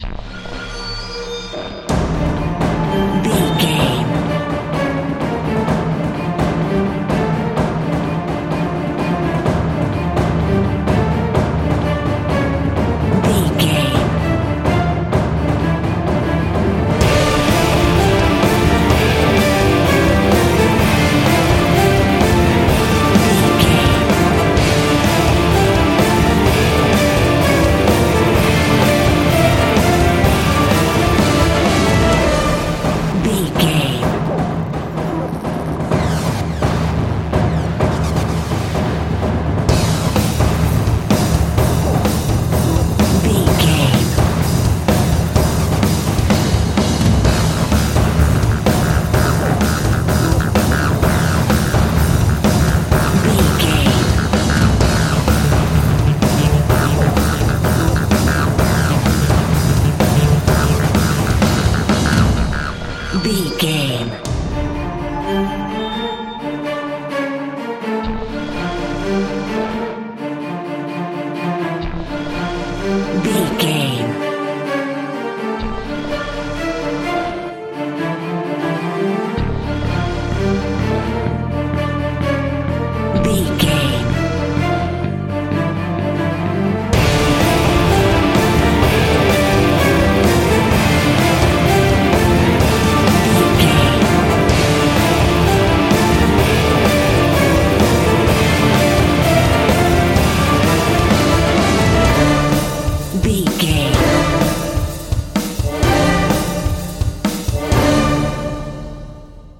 In-crescendo
Thriller
Aeolian/Minor
Fast
scary
ominous
dark
eerie
drums
strings
horror
wood wind